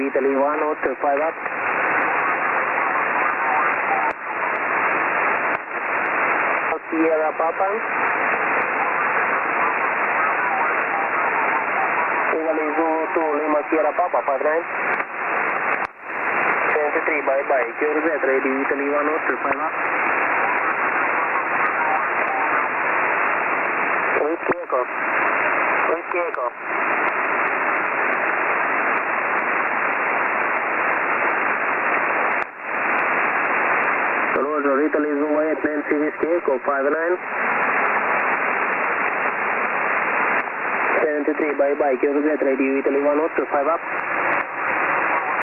RI1O EU-119 20mt SSB 20/08/2014